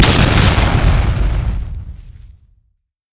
SFX炸弹爆炸音效下载